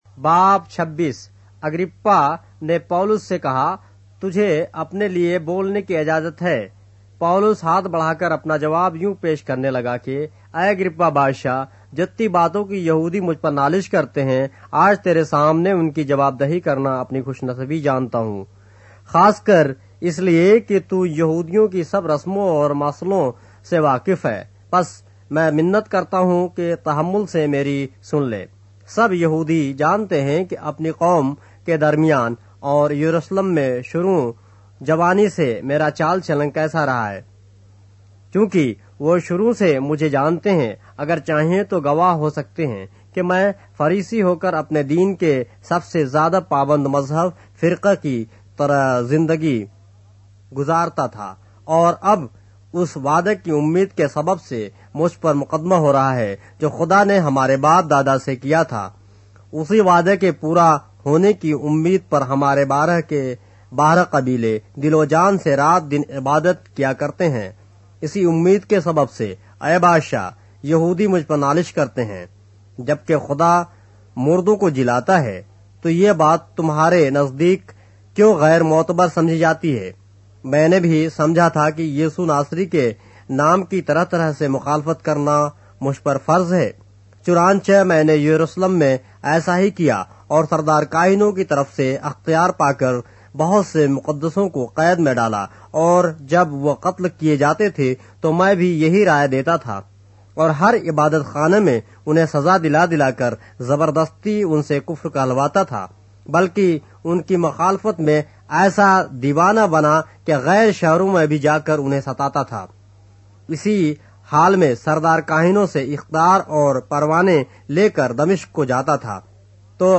اردو بائبل کے باب - آڈیو روایت کے ساتھ - Acts, chapter 26 of the Holy Bible in Urdu